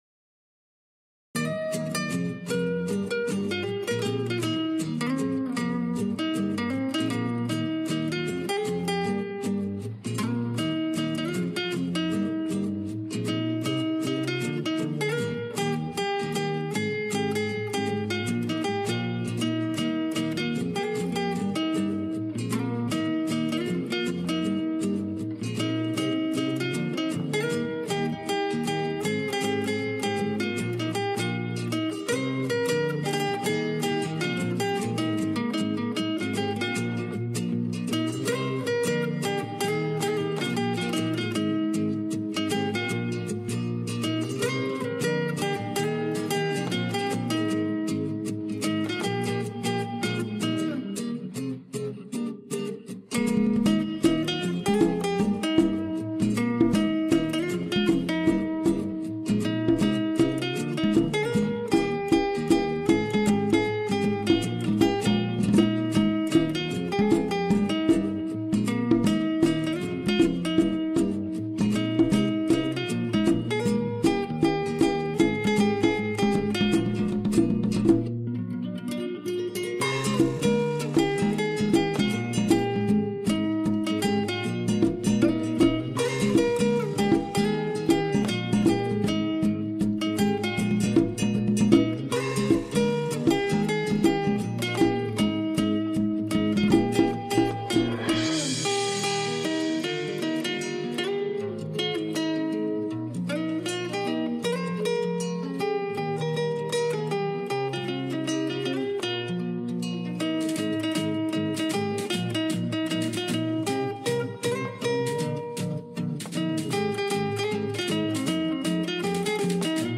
Percussion
I recorded the snare with brushes, and the cymbal swell.